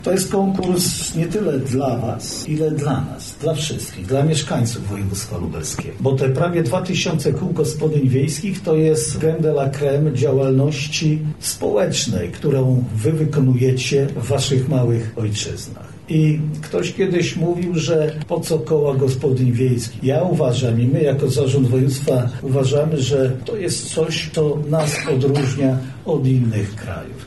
stawiar – mówi Jarosław Stawiarski, Marszałek Województwa Lubelskiego.